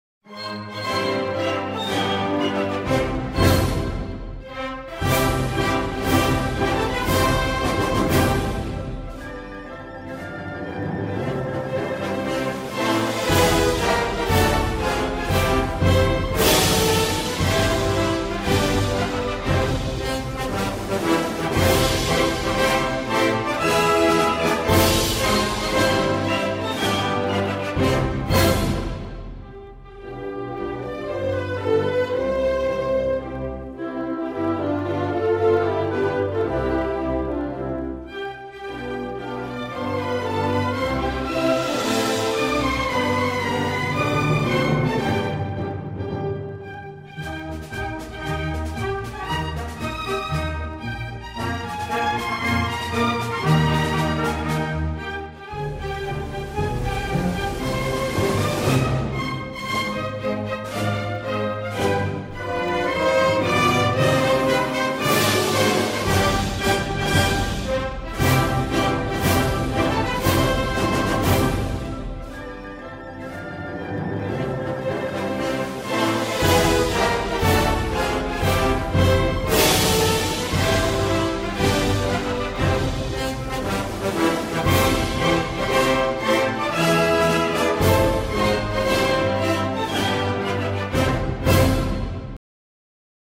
[2] Pista del Himno Nacional (CON voces)